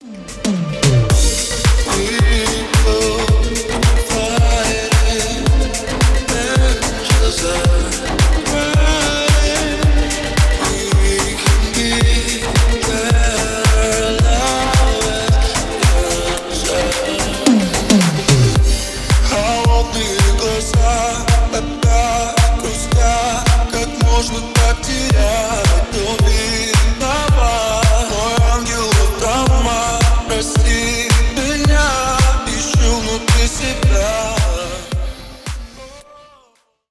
Ремикс # спокойные